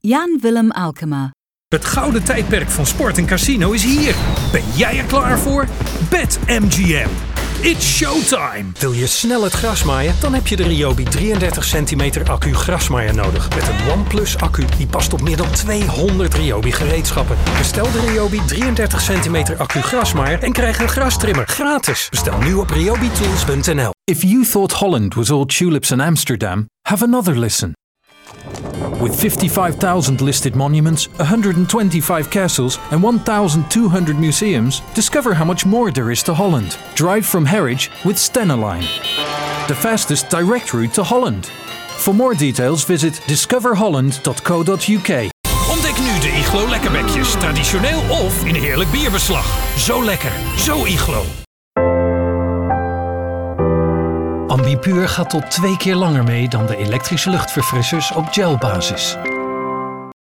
All our talent work with authentic languages, and native accents for English-speaking projects.
Showreel 0:00 / 0:00